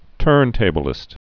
(tûrntābə-lĭst, -blĭst)